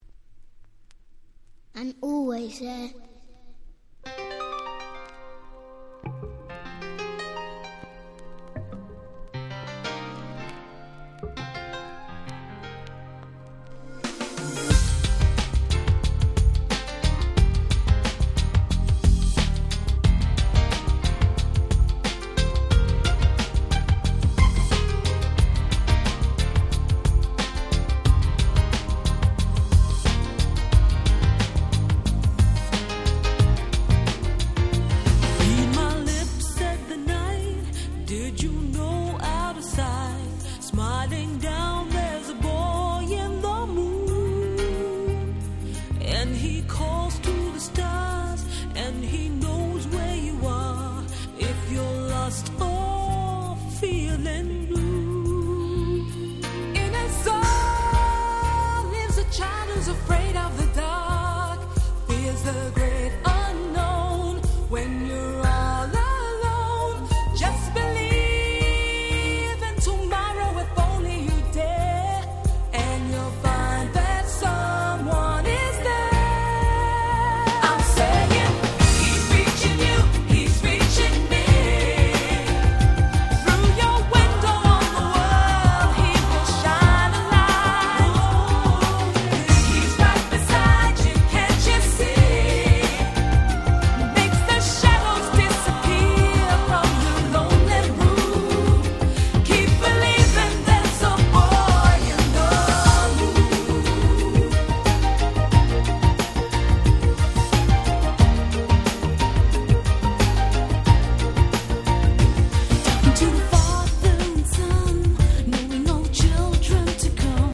93' Nice UK Street Soul !!
爽やかですが少しいなたい、そんな絶妙なバランスの好曲。